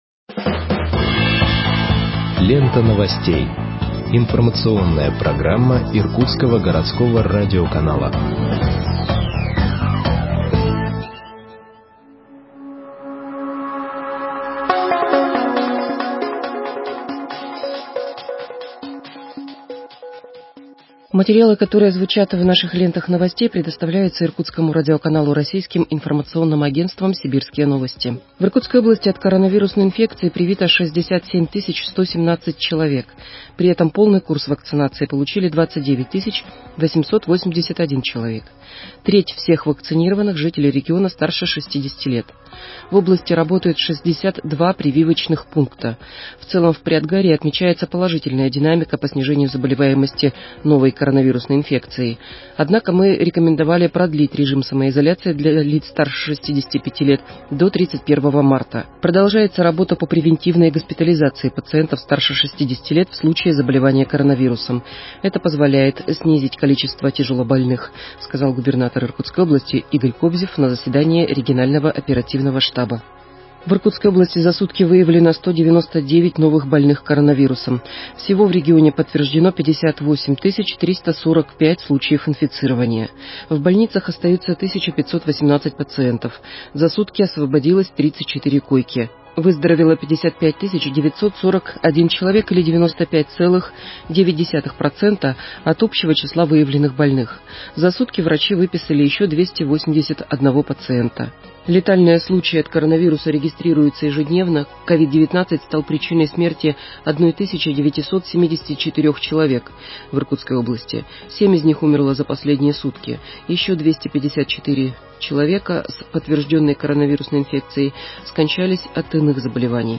Выпуск новостей в подкастах газеты Иркутск от 12.03.2021 № 2